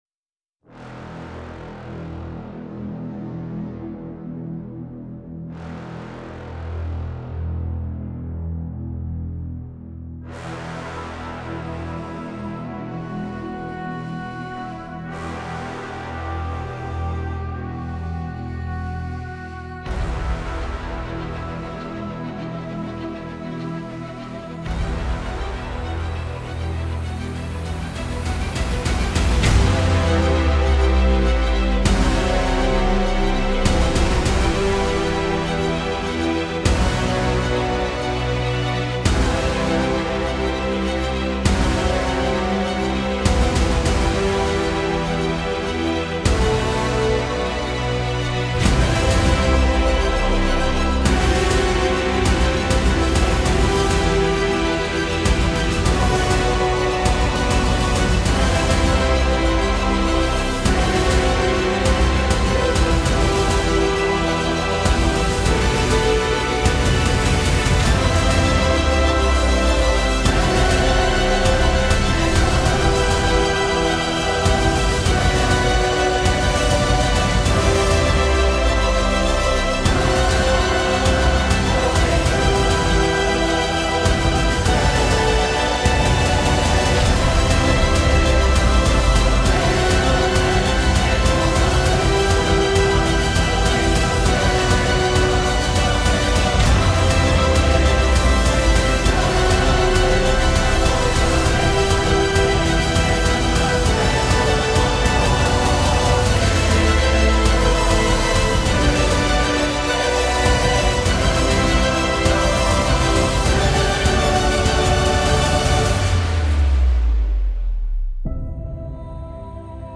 Genre: filmscore.